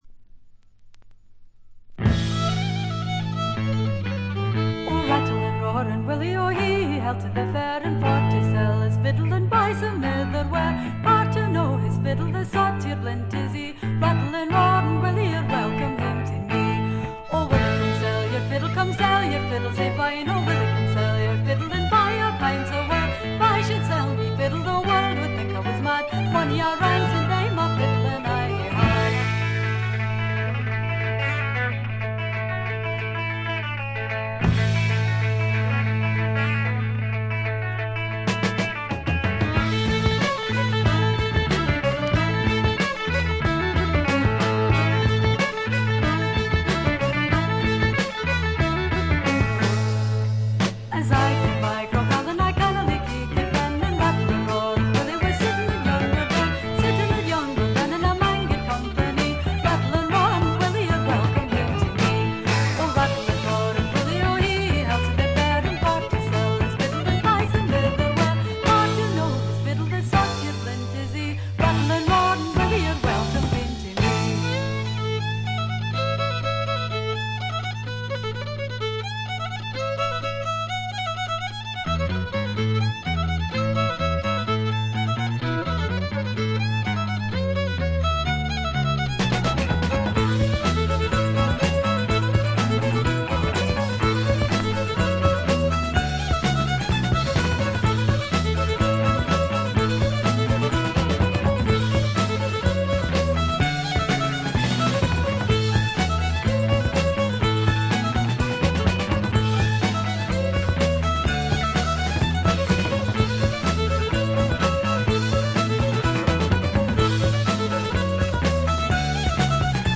他は軽微なチリプチが少々。
試聴曲は現品からの取り込み音源です。